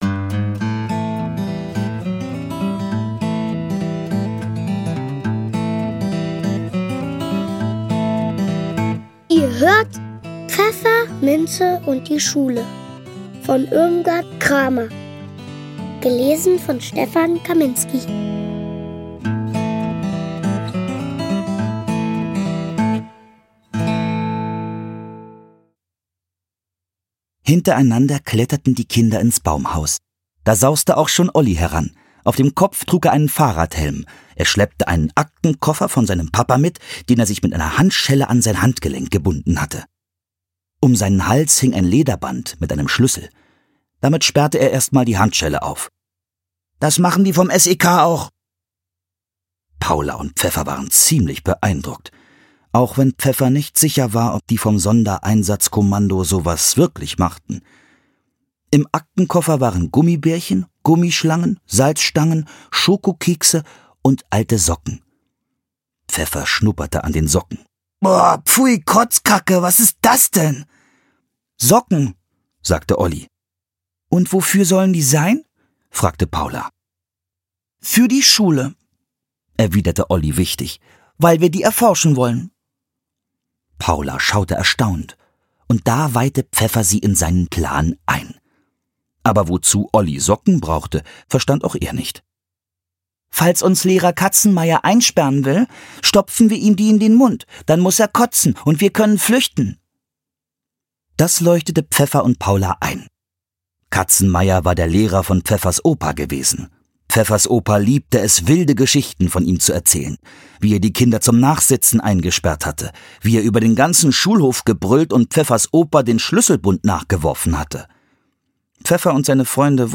Stefan Kaminski (Sprecher)
Schlagworte Abenteuer • Einschulung • Freunde • Hörbuch; Lesung für Kinder/Jugendliche • Kinder • Kuscheltier • Lehrerin • Schule • Schulklasse • Schulstart • Schultüte